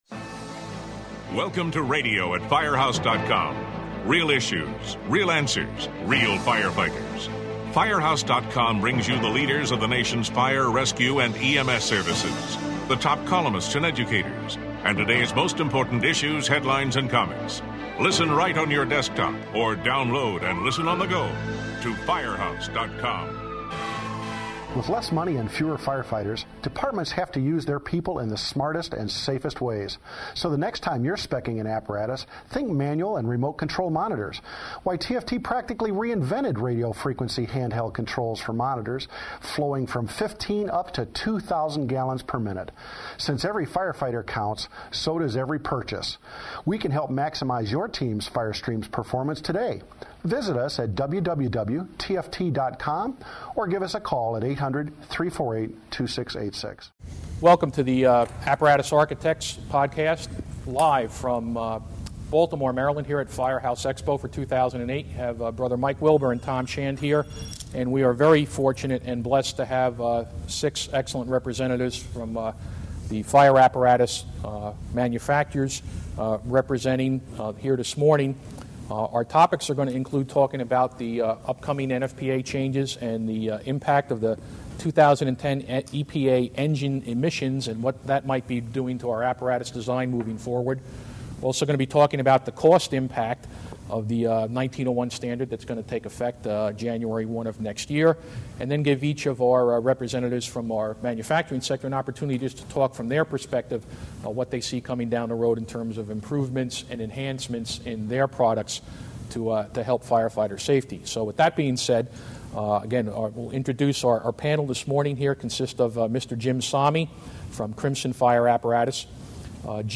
The Apparatus Architects: Manufacturer's Roundtable from Firehouse Expo